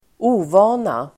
Uttal: [²'o:va:na]